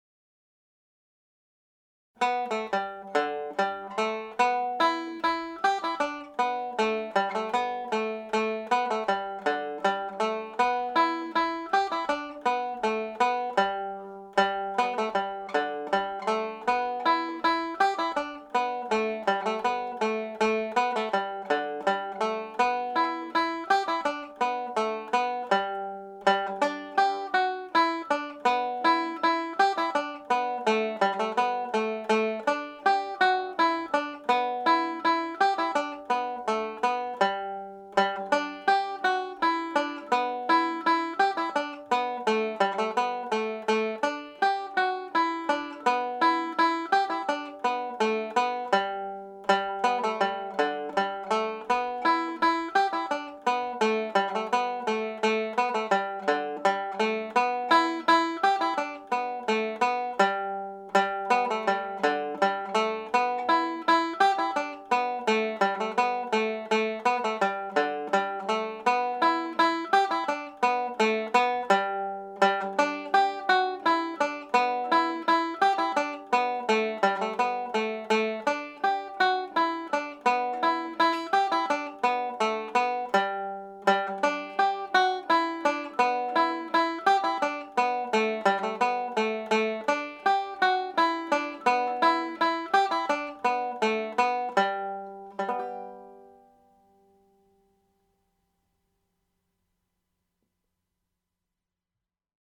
Maggie In The Wood played at polka speed
maggie-in-the-woods_fast.mp3